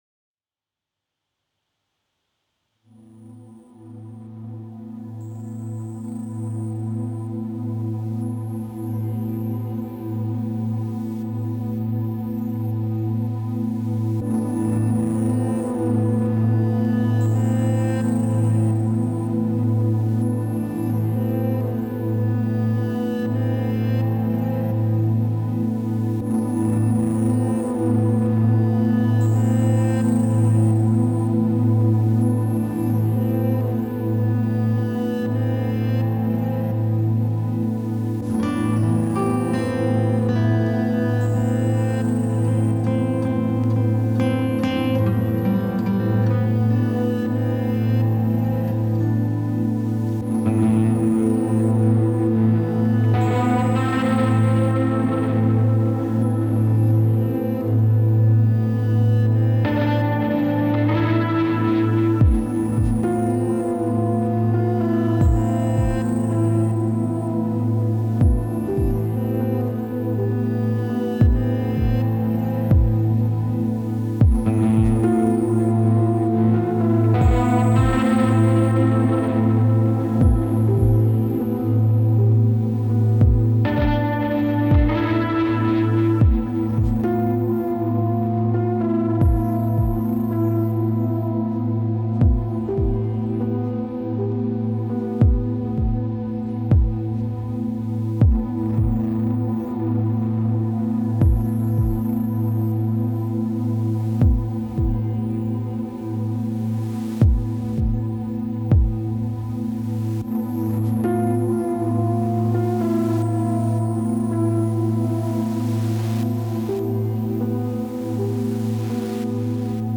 No synths on this. Just resampling and also a SP303 for some FX on the Cue out.
Acoustic Guitar, Electric Guitar (drone is EG too), Piano > OTMK2 (Kick sample in OT).